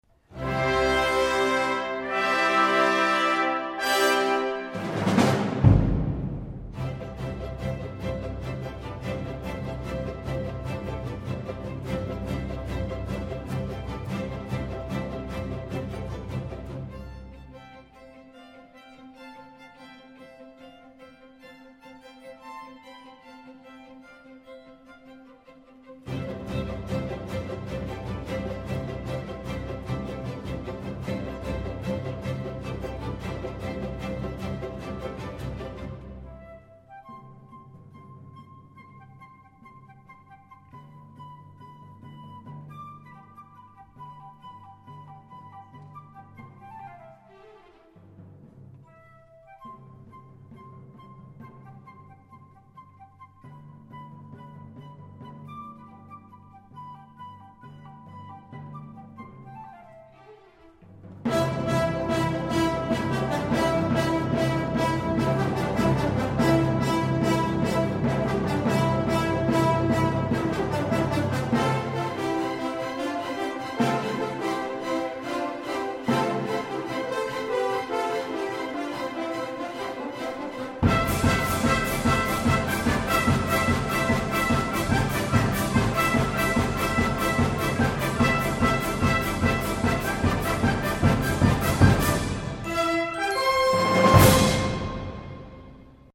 去年的現場音樂會錄音
這個版本的合唱部分，令人喜歡
柔軟的聲音